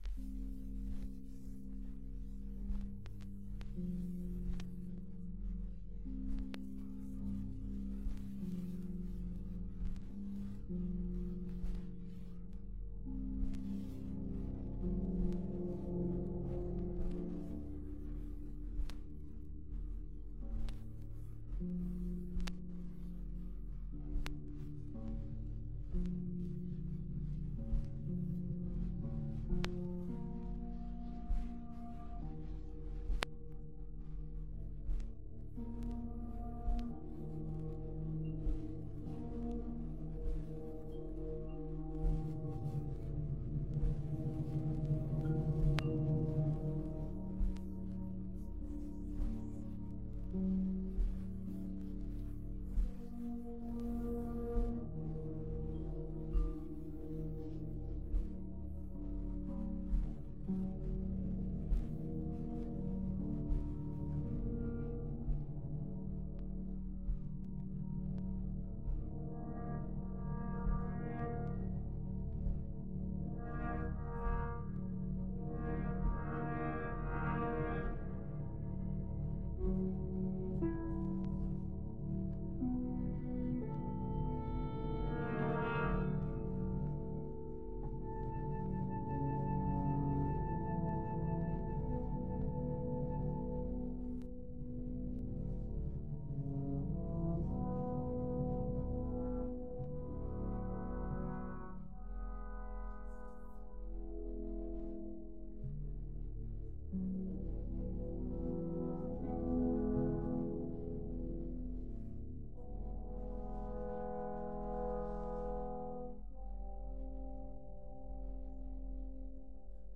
(Studio Recording, 1974)